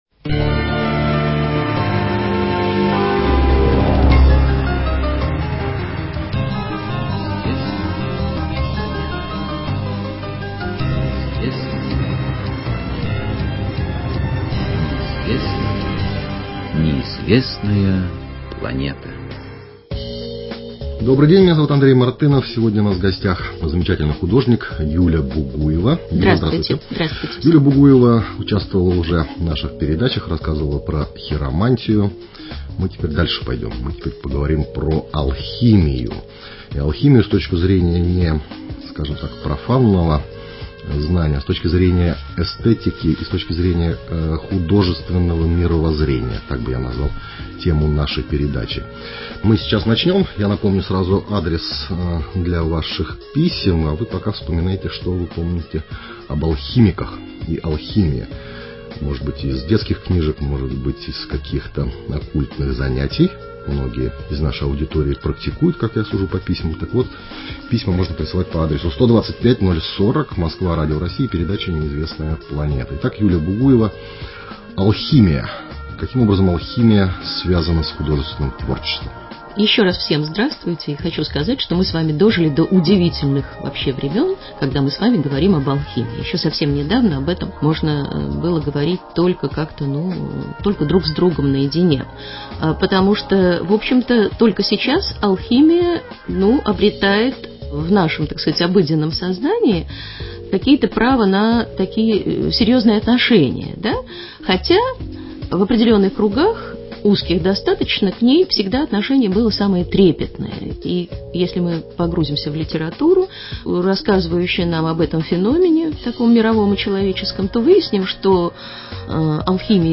/ Радио России / Радио «Маяк» Закон Гермеса Трисмегиста